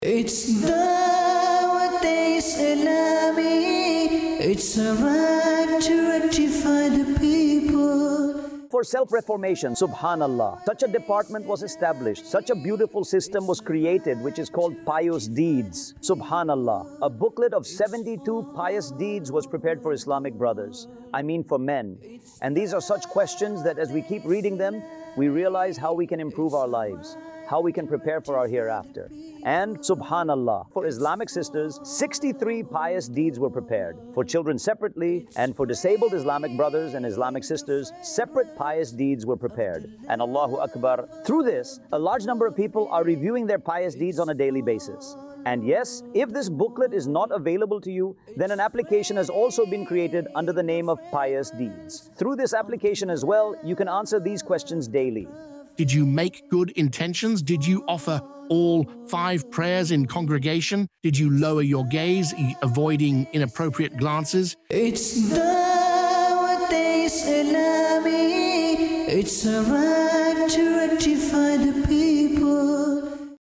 khutba
Pious Deeds | Department of Dawateislami | Documentary 2026 | AI Generated Audio